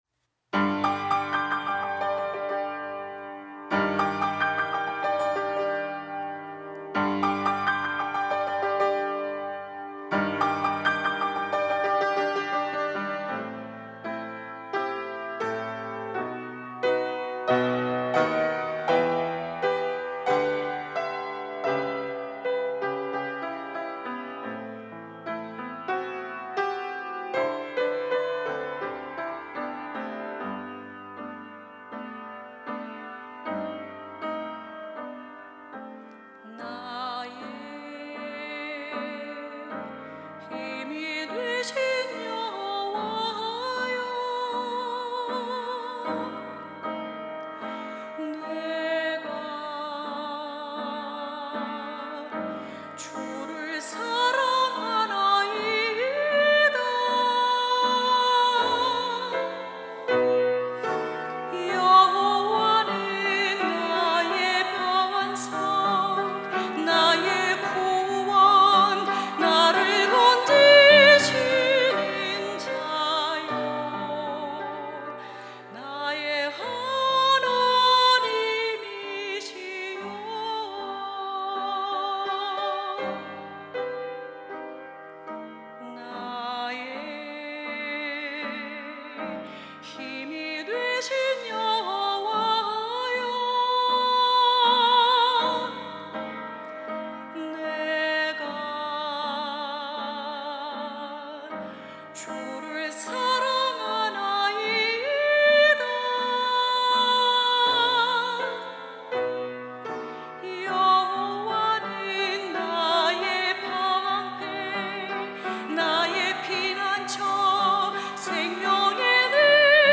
특송과 특주 - 나의 힘이 되신 여호와여